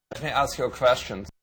Bruno Movie Sound Bites
ask_you_a_question.wav